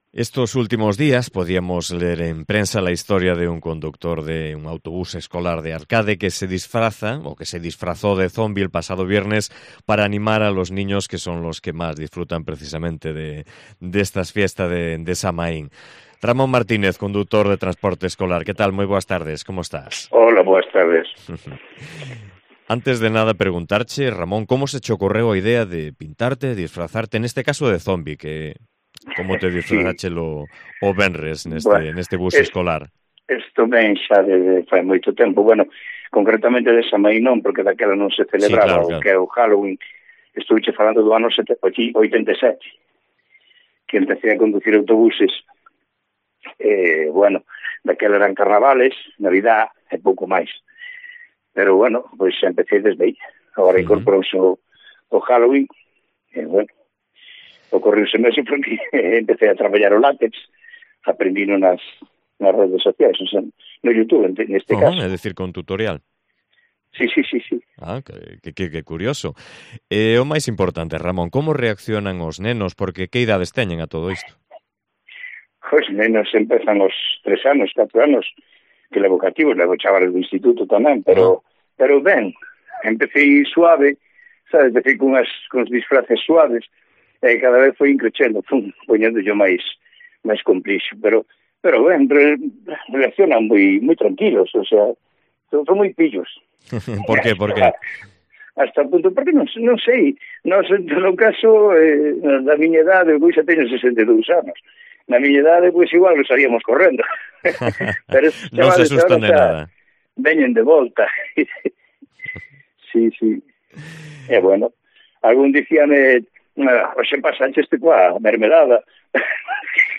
Entrevistamos a este conductor que se disfraza de zombie para celebrar con los más pequeños el Samaín